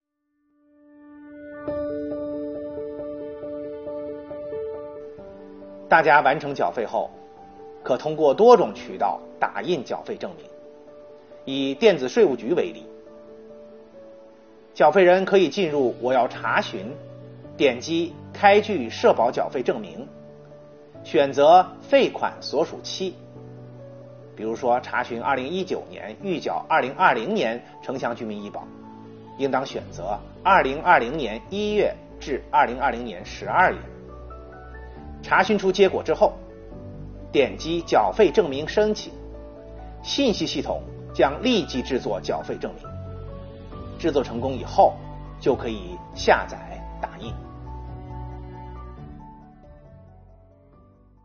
近日，国家税务总局推出“税务讲堂”课程，国家税务总局社会保险费司副司长杜志农介绍城乡居民基本养老保险费和基本医疗保险费征缴服务（以下简称城乡居民“两险”）。